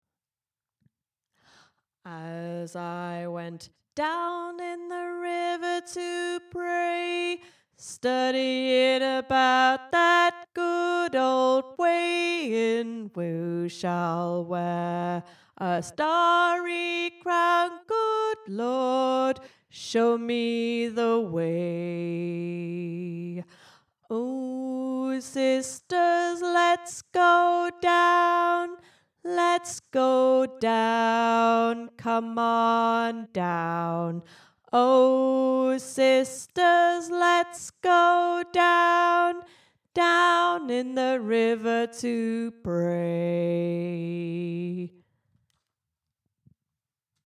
down-in-the-river-to-pray-Bass.mp3